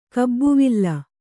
♪ kabbuvilla